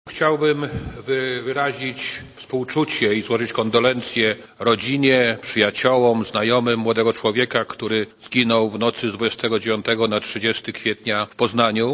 Posłuchaj ministra Kalisza